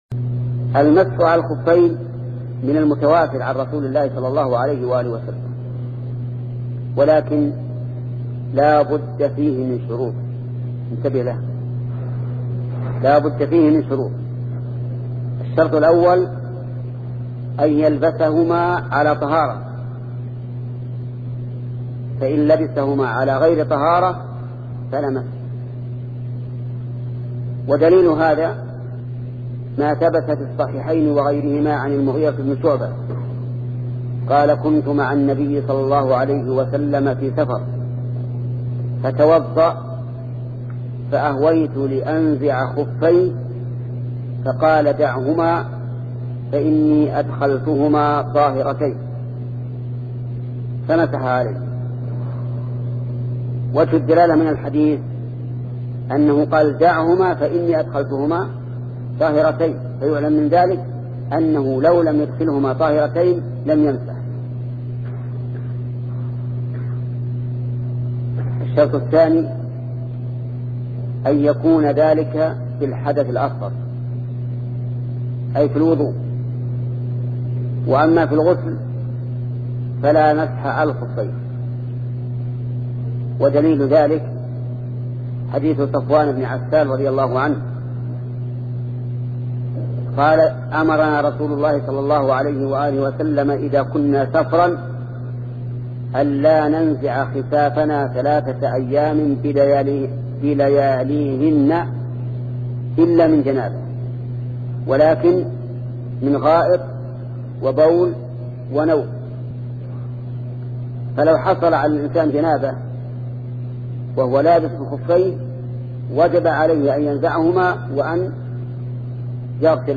فوائد من دروس المسجد النبوي